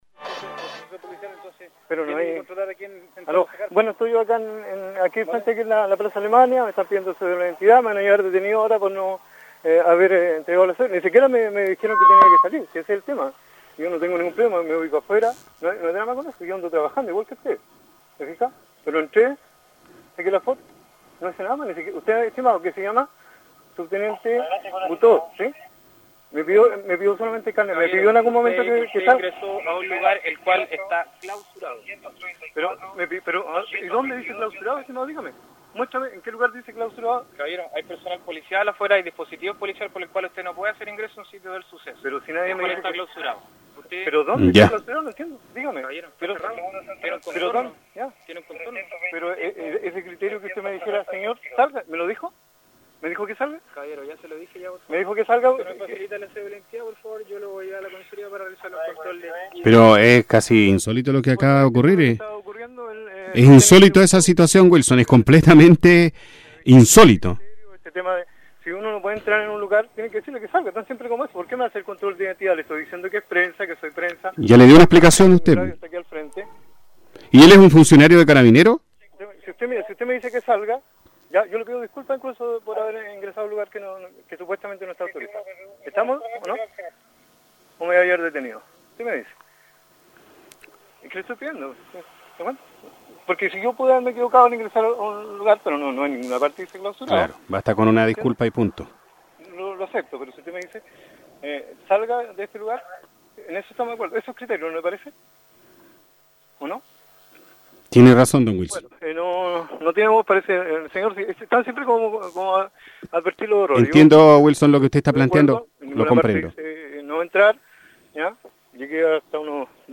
DESPACHO desde el sitio del suceso...infraccion y control de identidad..